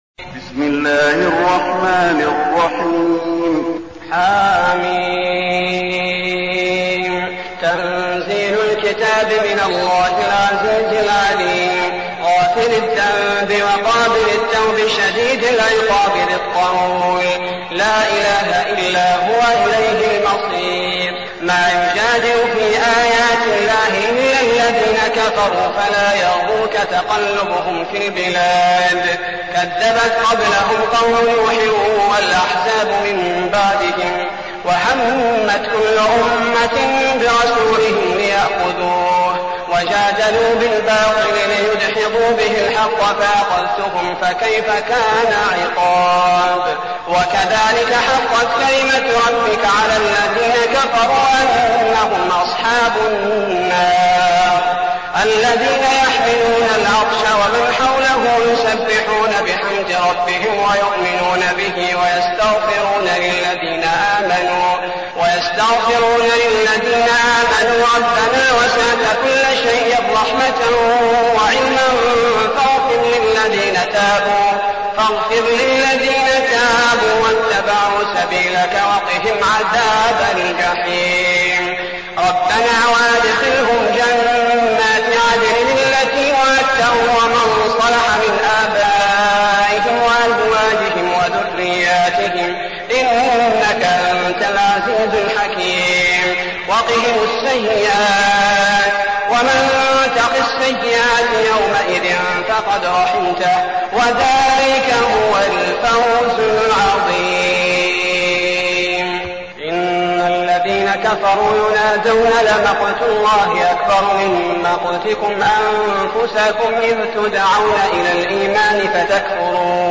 المكان: المسجد النبوي غافر